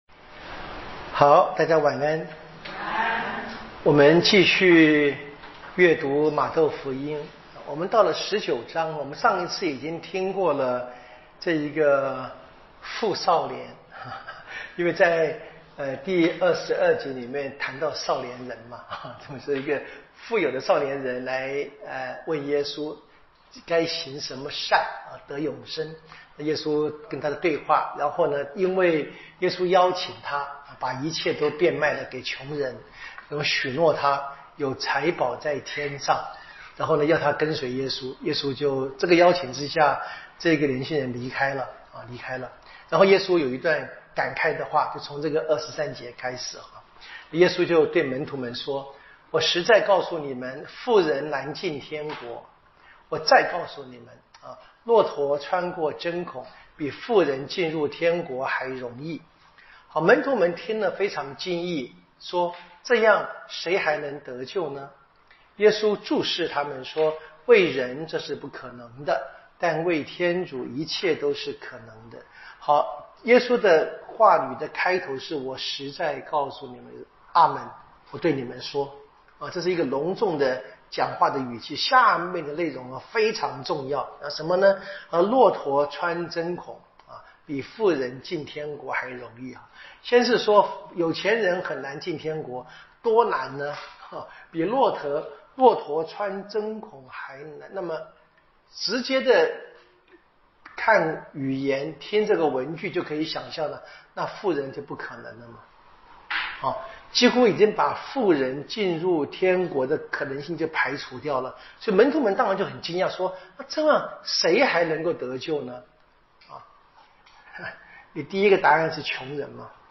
圣经讲座】《玛窦福音》